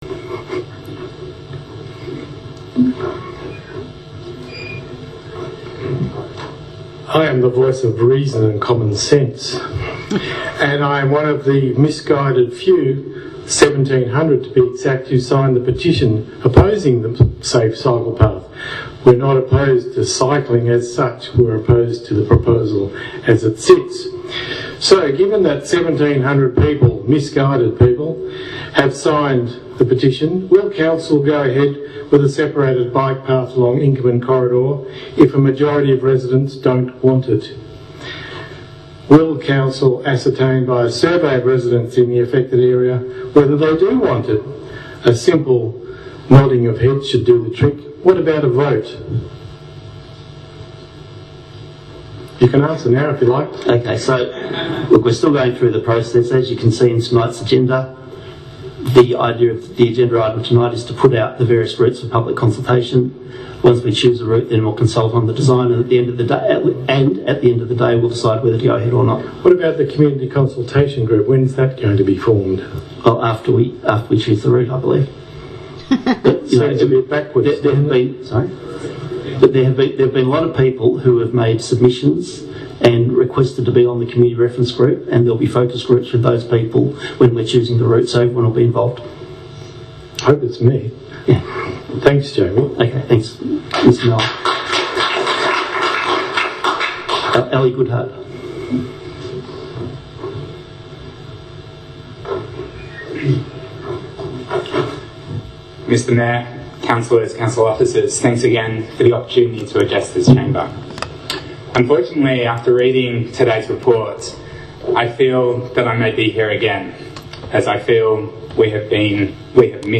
Please listen very carefully to this short extract from last night’s public participation segment from the council meeting.